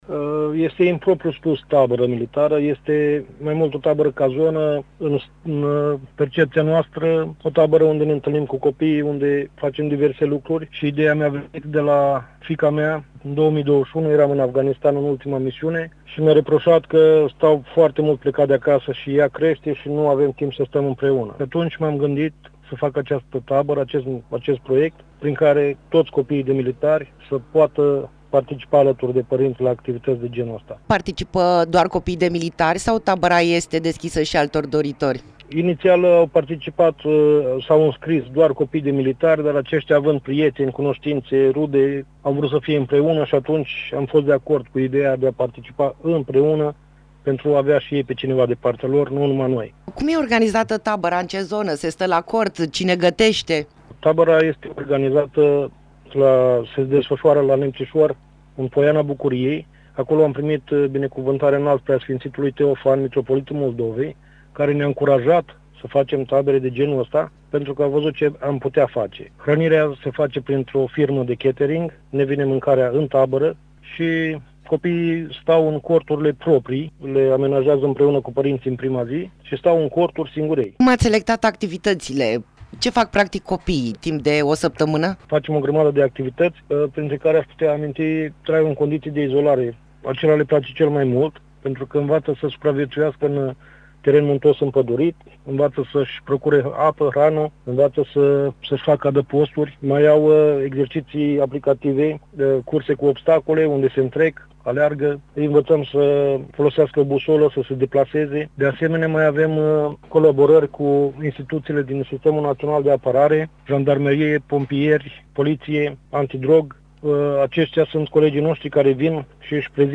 Interviu-tabara-cazona.mp3